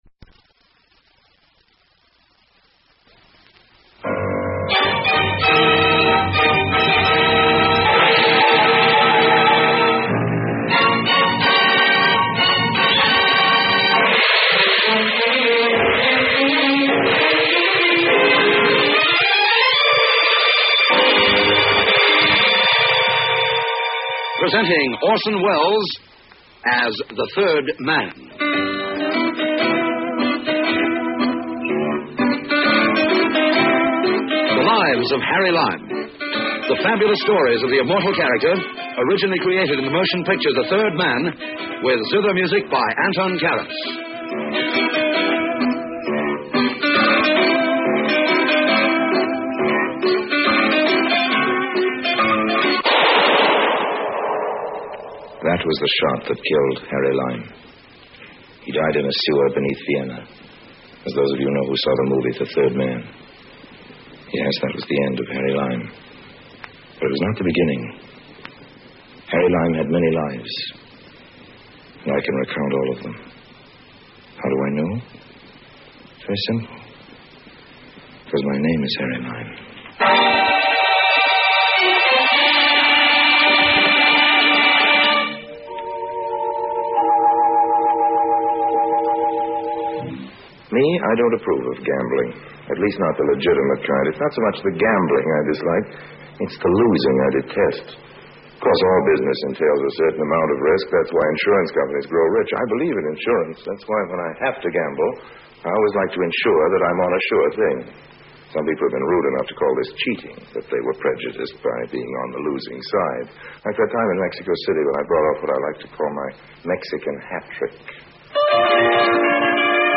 The Adventures of Harry Lime is an old-time radio programme produced in the United Kingdom during the 1951 to 1952 season. Orson Welles reprises his role of Harry Lime from the celebrated 1949 film The Third Man. The radio series is a prequel to the film, and depicts the many misadventures of con-artist Lime in a somewhat lighter tone than that of the film.